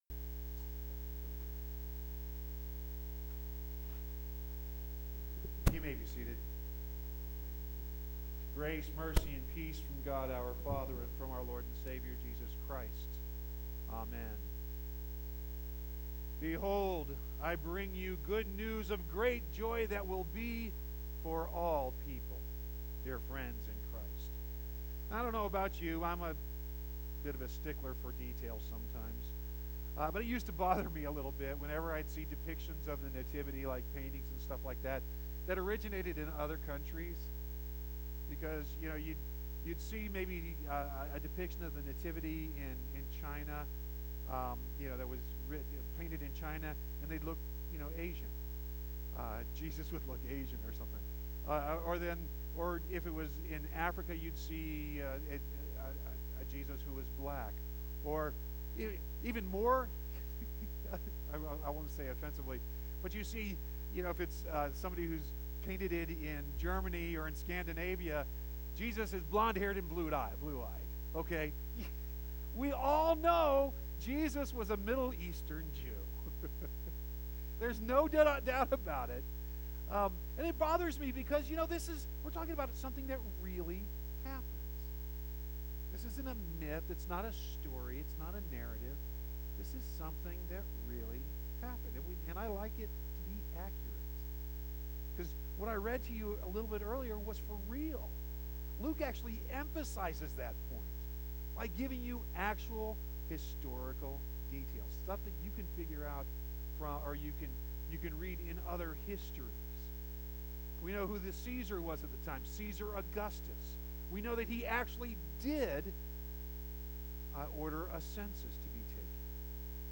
Christmas Eve 12.24.19